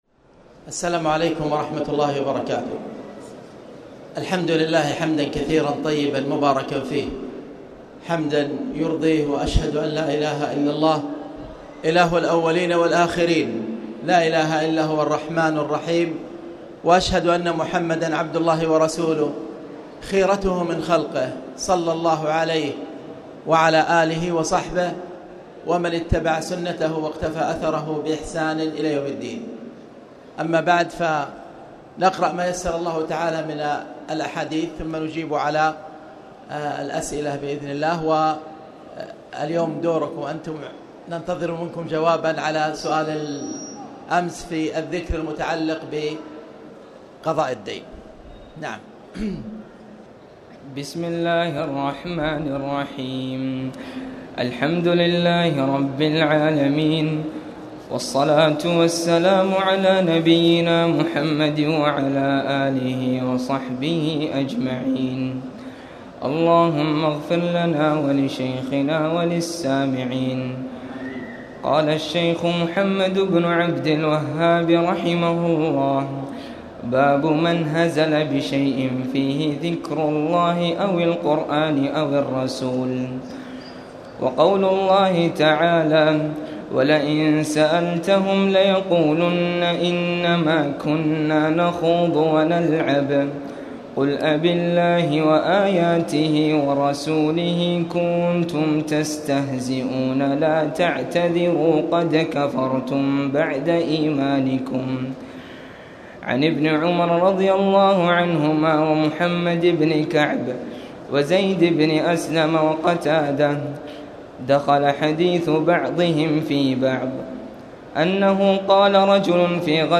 تاريخ النشر ١٧ رمضان ١٤٣٨ هـ المكان: المسجد الحرام الشيخ: خالد بن عبدالله المصلح خالد بن عبدالله المصلح باب من هزل بشئ فيه ذكر الله